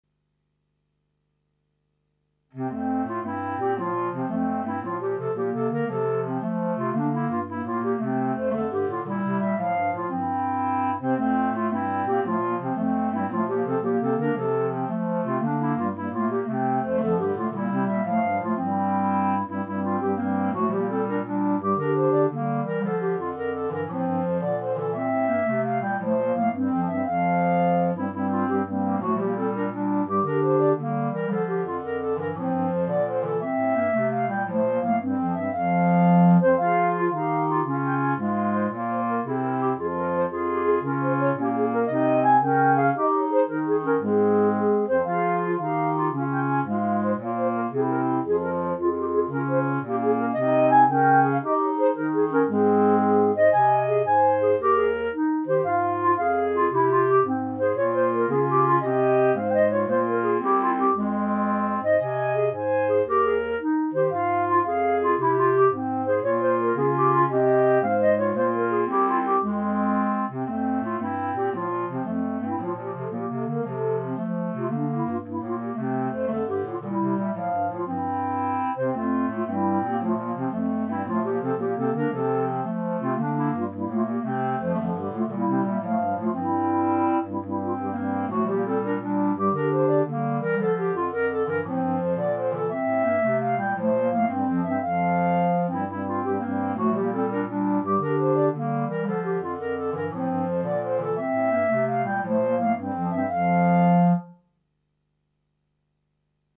Clarinet Quartet
Instrumentation: 3 Clarinet, Bass Clarinet